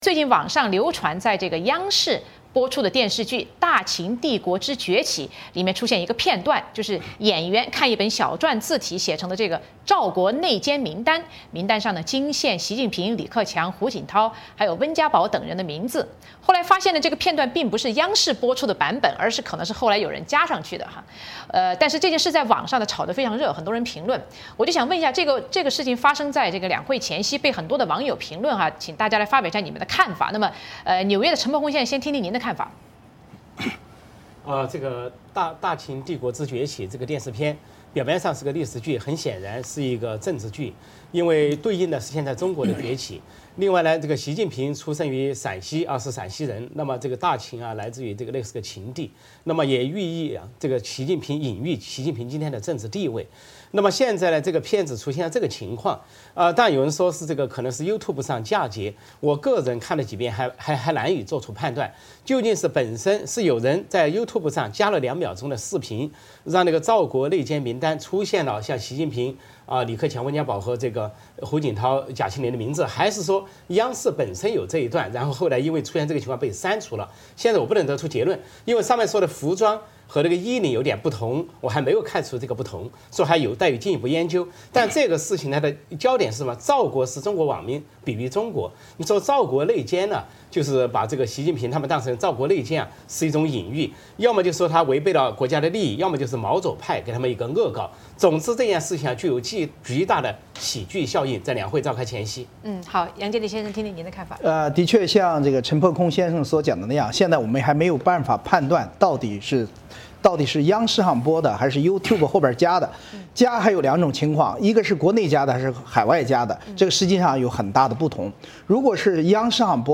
对此三位嘉宾发表了自己的看法。